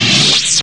saberSwitchOff.wav